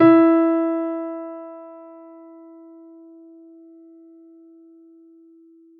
piano
E4.wav